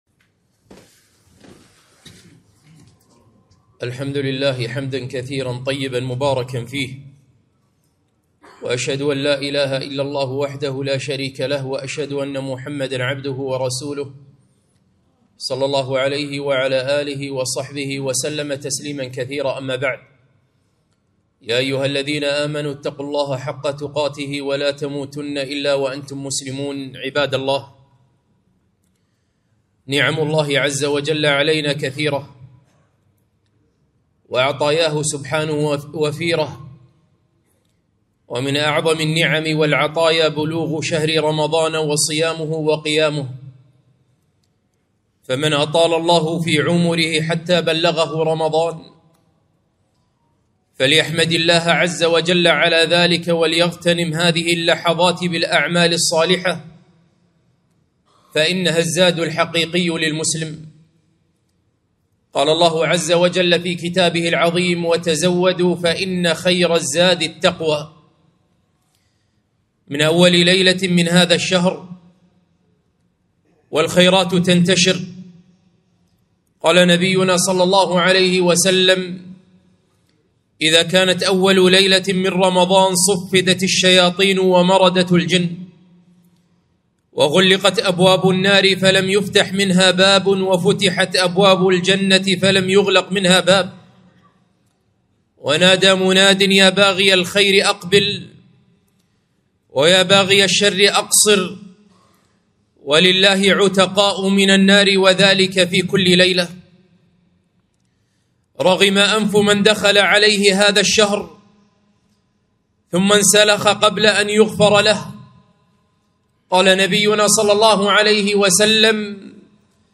خطبة - هلموا لشهر الخير